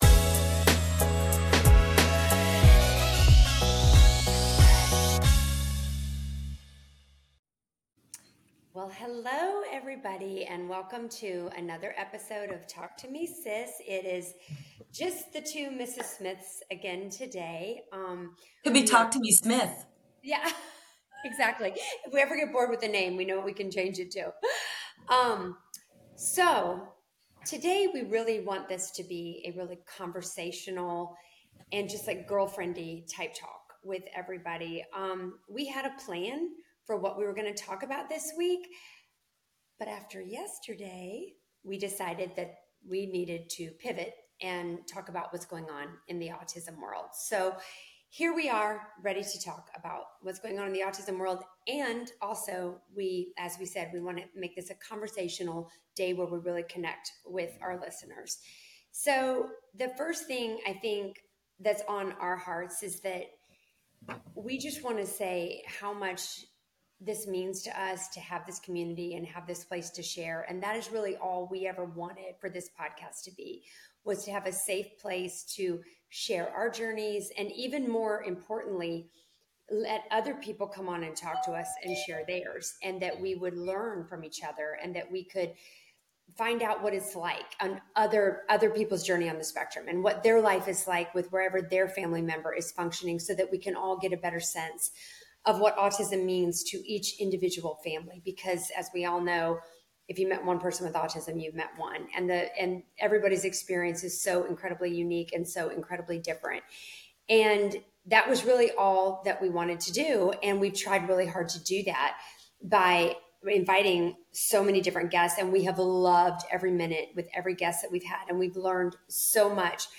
Along the way we share some listener messages from the UK, Canada, and Clemson that bring in personal stories about connection and support. It is a natural conversation about belonging and looking out for each other.